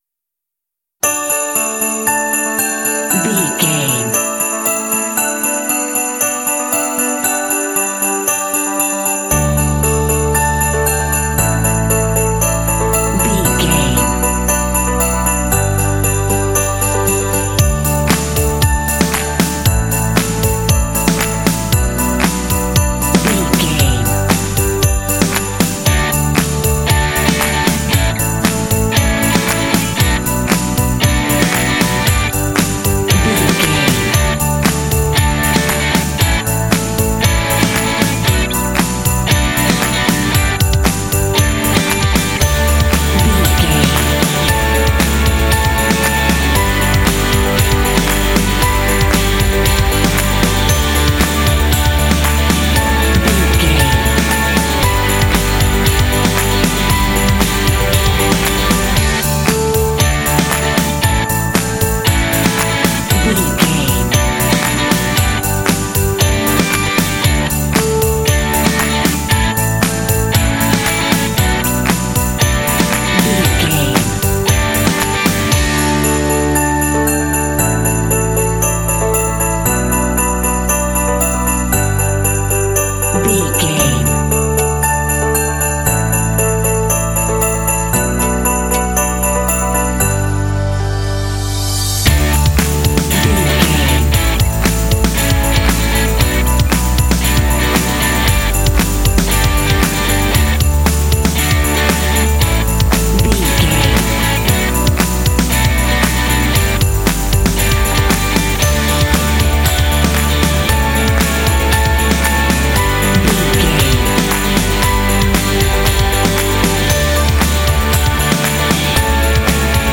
Ionian/Major
D
happy
uplifting
joyful
bouncy
festive
piano
bass guitar
electric guitar
contemporary underscore